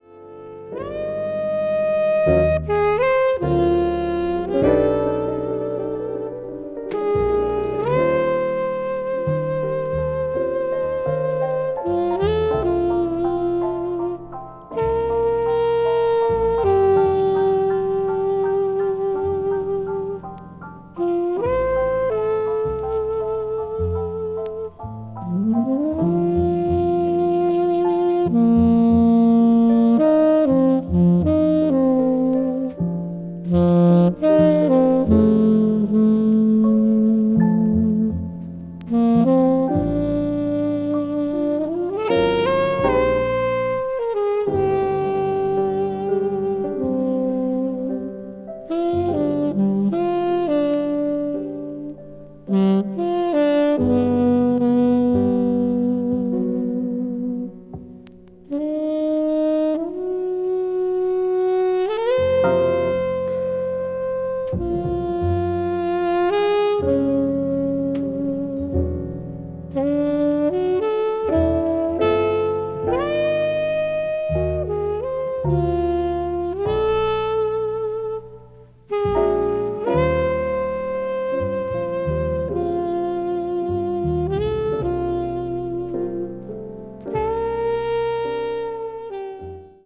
media : EX+/EX+(some slightly noises.)
piano
bass
drums
is a funky and groovy piece